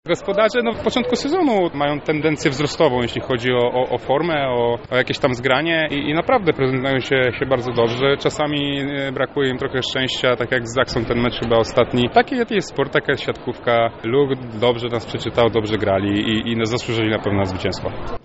Żółto-czarni nie oddali prowadzenia do końca seta, wygrywając 25:12. LUK zasłużył na zwycięstwo – mówił po meczu przyjmujący Projektu, Bartosz Kwolek.